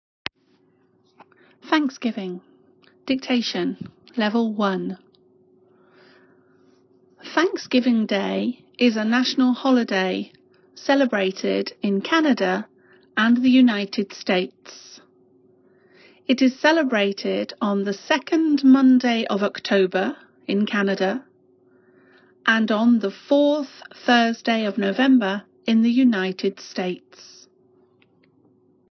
Exercise 2 : THANKSGIVING DICTATION
thanksgiving-dictation-1.m4a